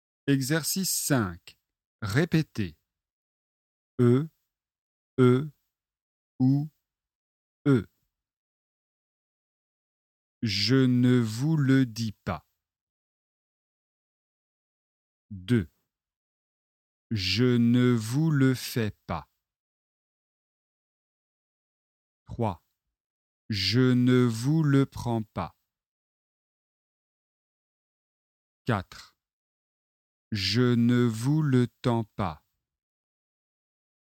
• Leçon de phonétique et exercices de prononciation
Pour les exercices 1 à 7, tous les « e » sont prononcés.
🔷 Exercice 5 : répétez
e / e / ou / e